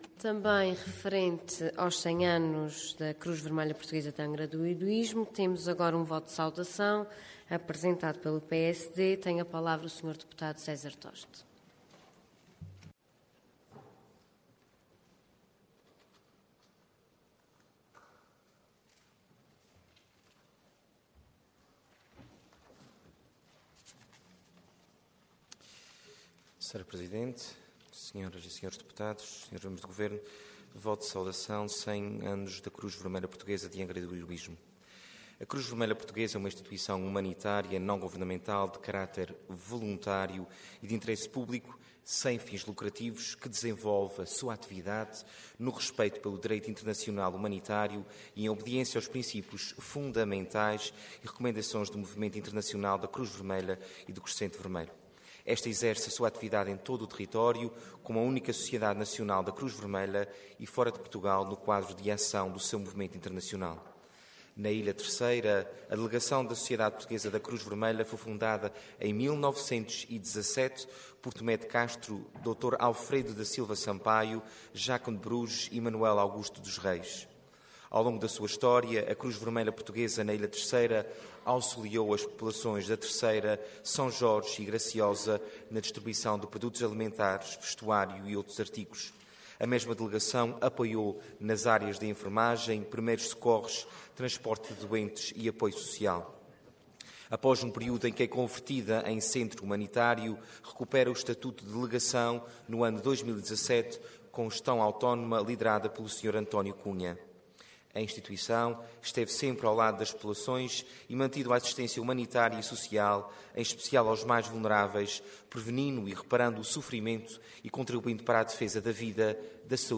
Intervenção Voto de Saudação Orador César Toste Cargo Deputado Entidade PSD